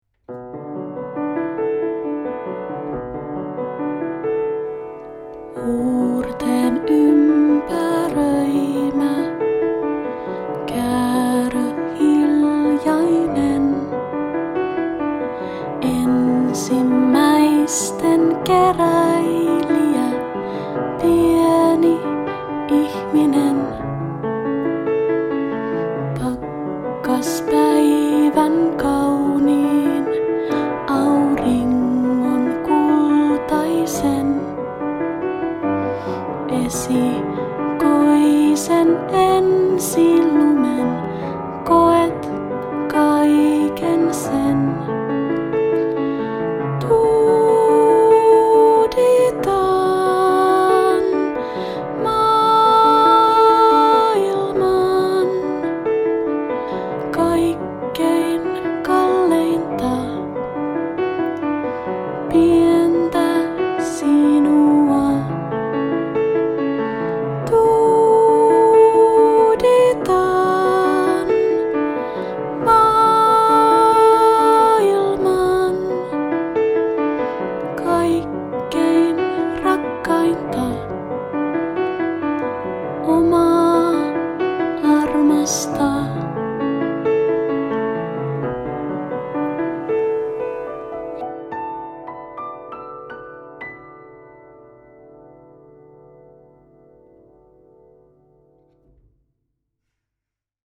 Kappaleista löytyy rauhallisia lauluja hellittelyhetkiin vauvan kanssa sekä reipastempoisia lauluja aktiivisiin leikkihetkiin ja itkun tyynnyttelyyn.
Olen äänittänyt sävellykset laulaen ja pianolla itseäni säestäen.